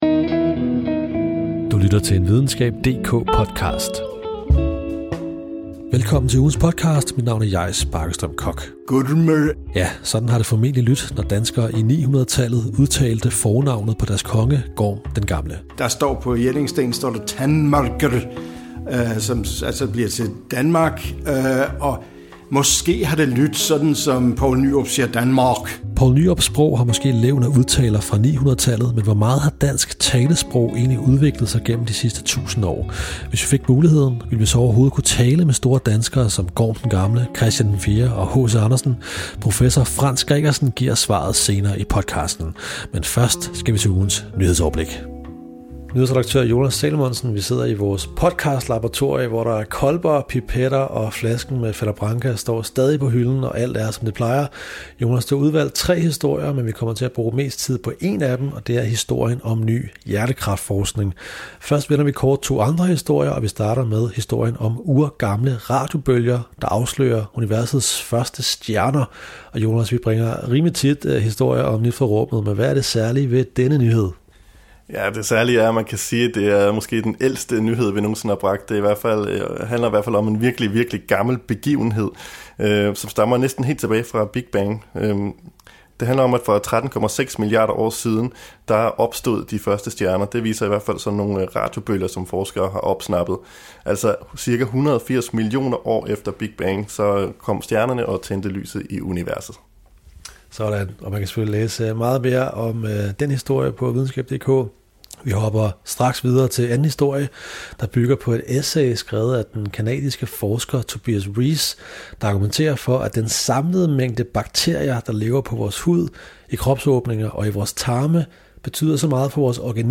Spørgsmålet er godt, men svaret er endnu bedre: »Kurrrmmmmrrrrr« eller »Gørrrmørrr« er to af sprogforskerens fantastiske bud på, hvordan Gorm præsenterede sig selv i døren.